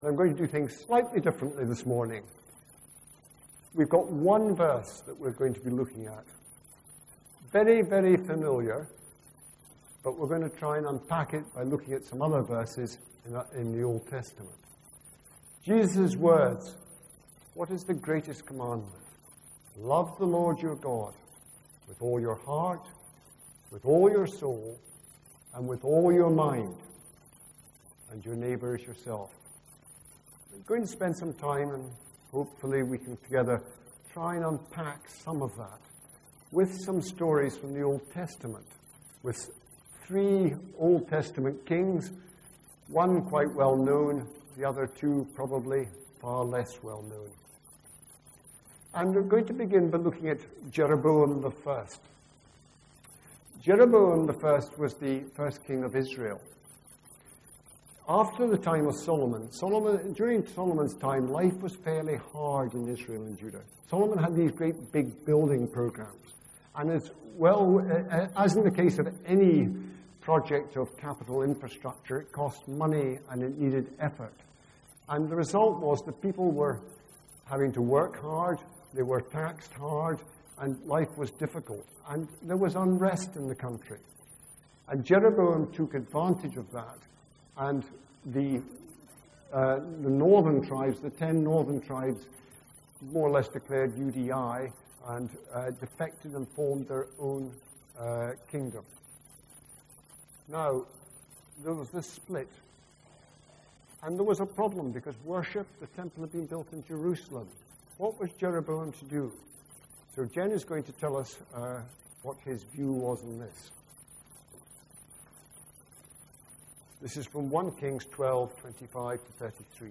Please click on the player below to hear the service, or right click on this link to download the sermon to your computer :